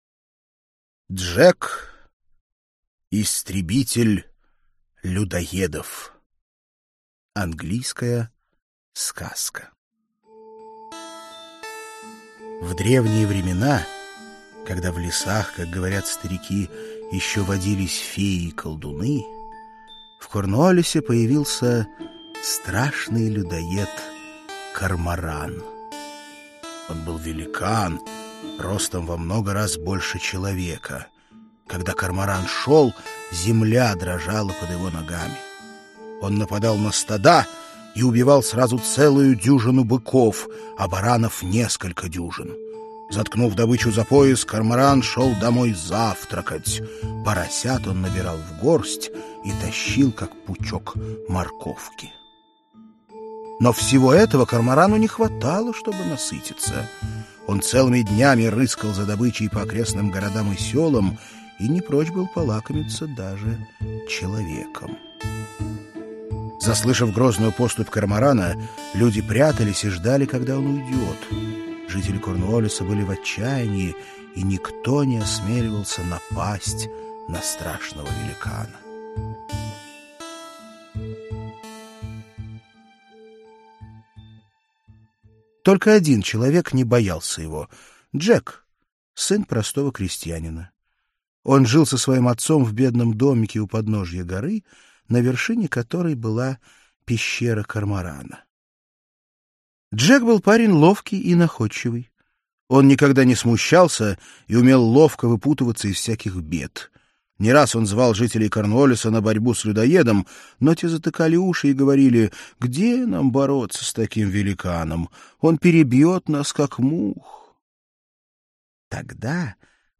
Аудиокнига Сказки народов мира 3 | Библиотека аудиокниг